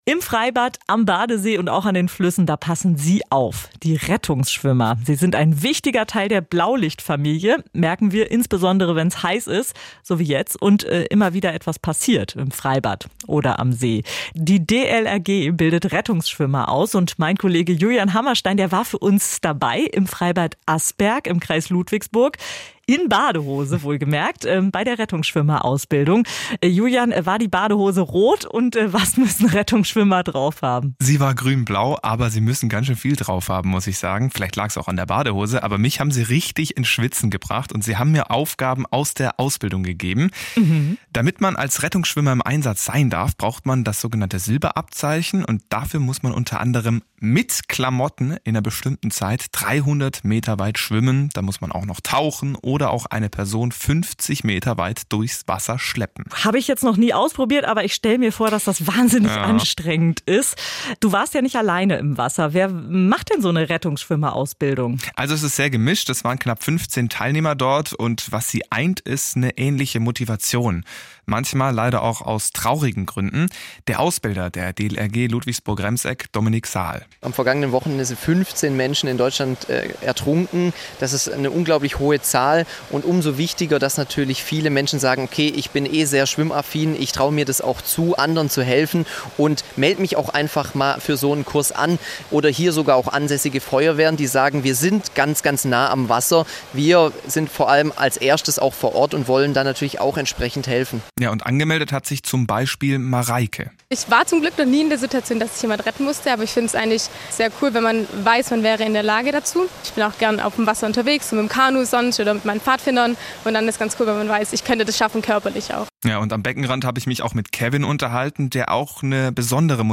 im Freibad Asperg im Kreis Ludwigsburg